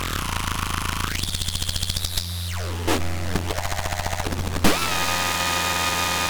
It’s part of recordings I did for my Syntrx II sample pack.
I have to say, it’s not un-cat-like, as sounds go.